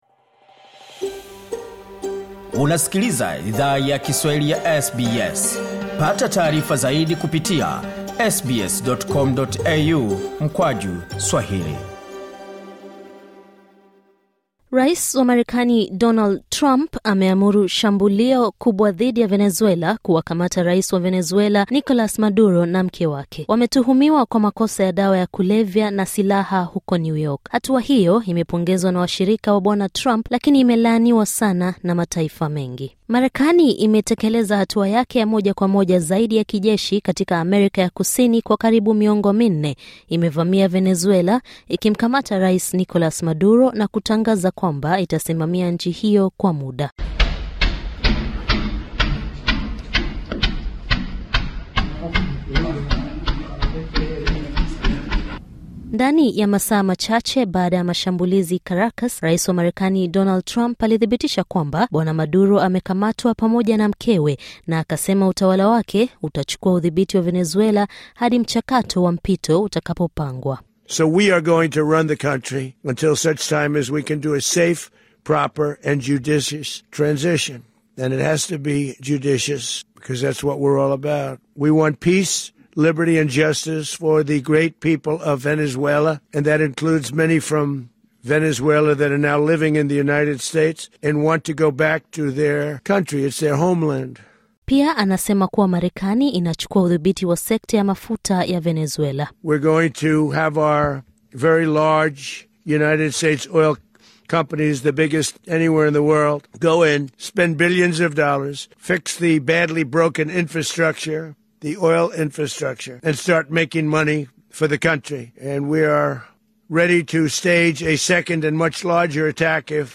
Taarifa ya habari:Marekani yavamia Venezuela na kumkamata rais Maduro na mkewe